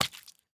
sounds / mob / tadpole / hurt4.ogg
hurt4.ogg